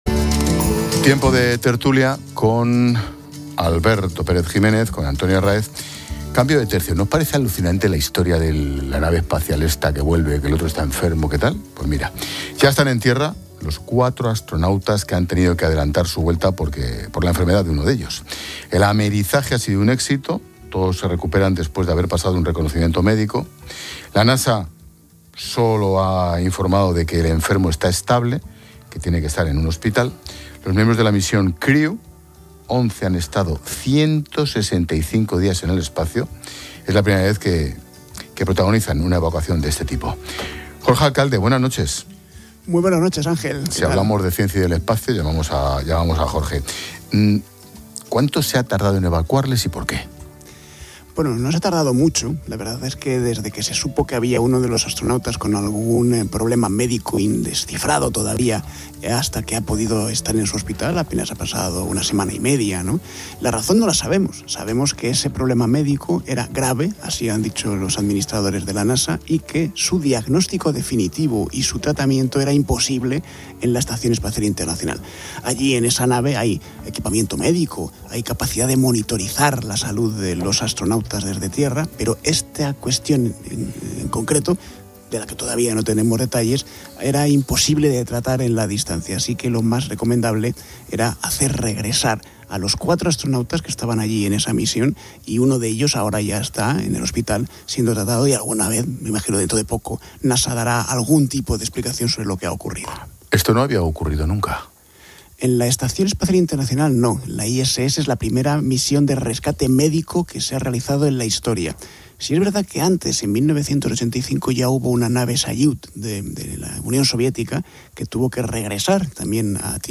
Expósito comprende cómo se ha producido el regreso de emergencia de una nave espacial por la enfermedad de un astronauta con el divulgador científico Jorge Alcalde